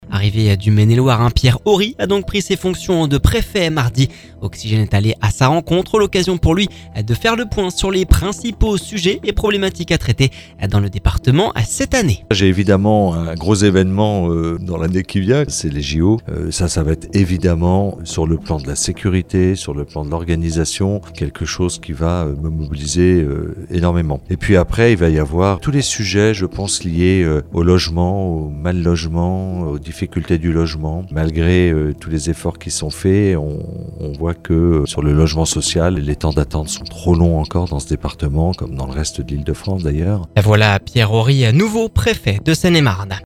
MELUN - Rencontre avec le nouveau préfet de Seine et Marne !